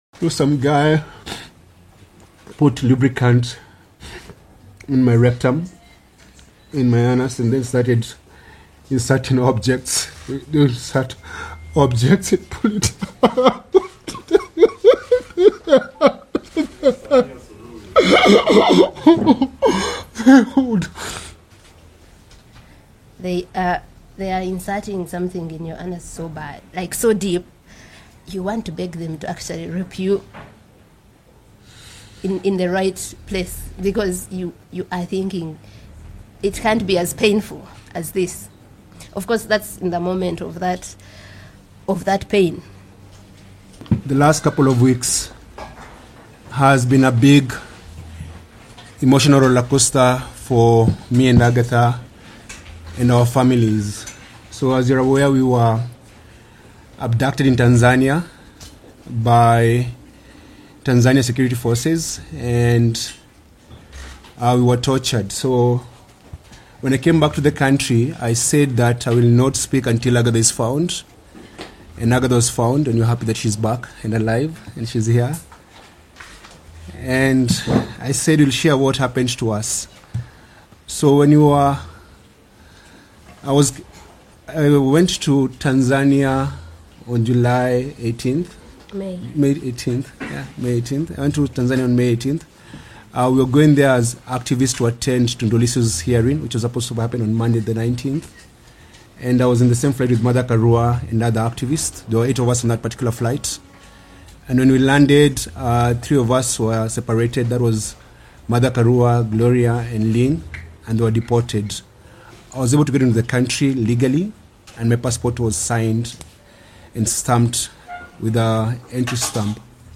Speaking vividly, the two activists recount alleged incidents of sexual torture, humiliation, and abuse at the hands of Tanzanian authorities.